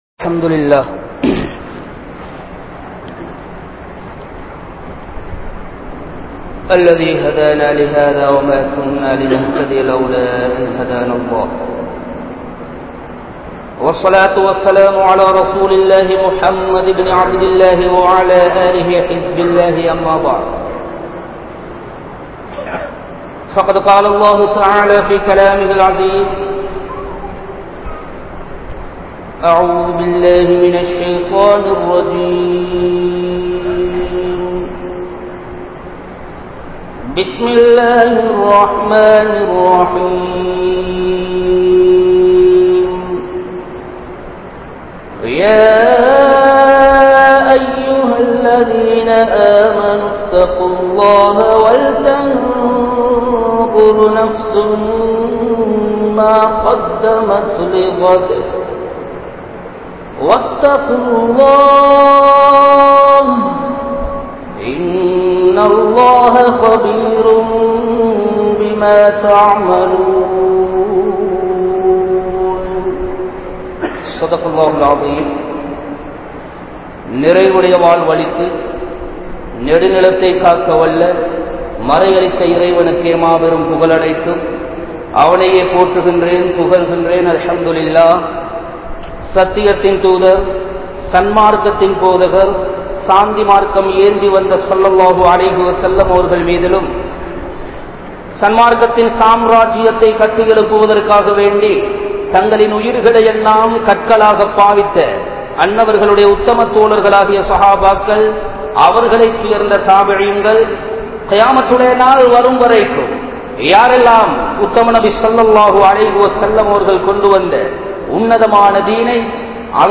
Oru Naal Ungalin Peyar JANAZA Aahum (ஒரு நாள் உங்களின் பெயர் ஜனாஸா ஆகும்) | Audio Bayans | All Ceylon Muslim Youth Community | Addalaichenai